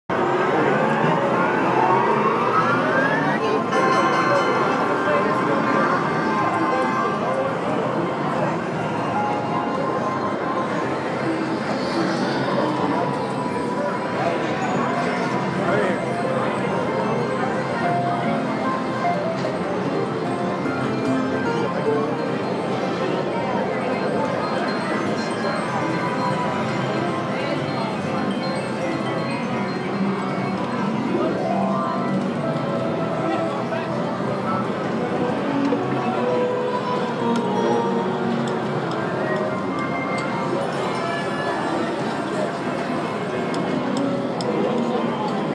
Casino
casino.m4a